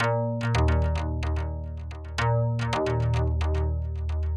电子吉他002
描述：电子吉他循环播放
标签： 110 bpm Hip Hop Loops Guitar Electric Loops 752.83 KB wav Key : Unknown
声道立体声